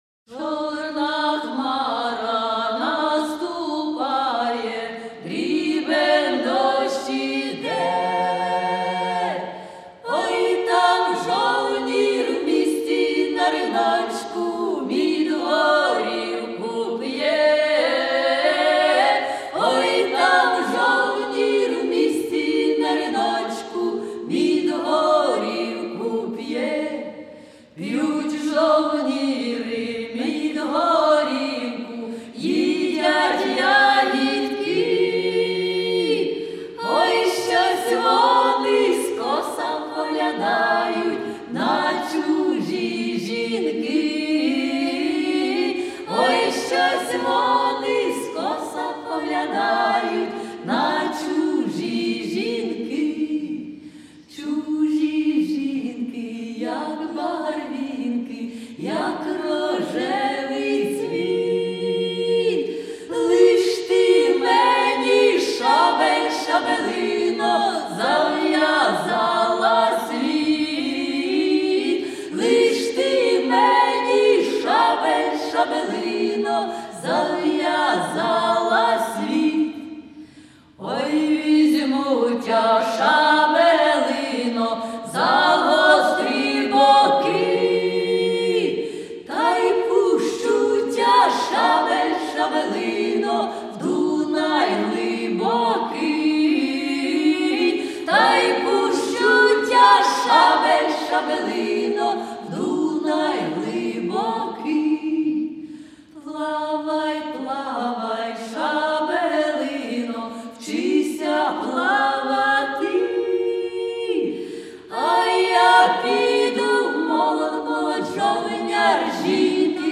(вояцька)